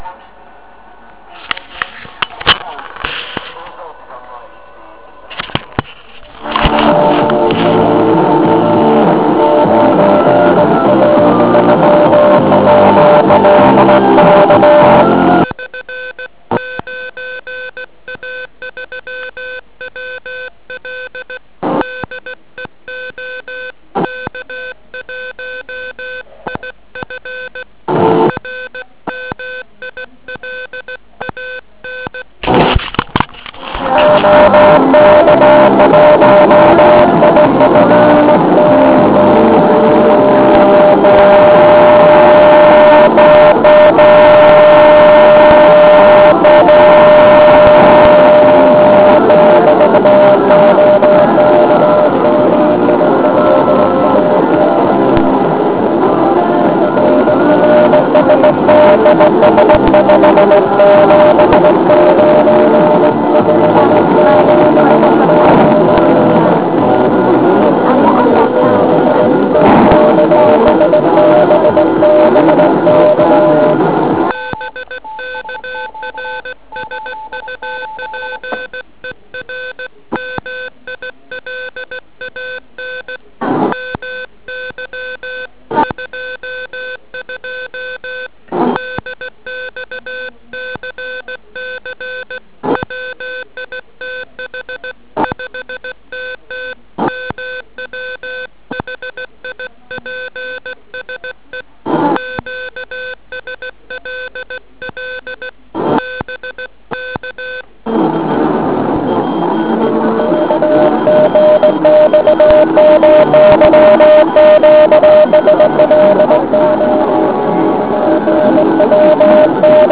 Hraje tam proto, proto�e je to super jednoduch� a lacin� r�dio.
Zkr�tka tr�nink na neodoln�m za��zen� se �irok�m propustn�m filtrem a s poslechem parazitn�ch p��jm�.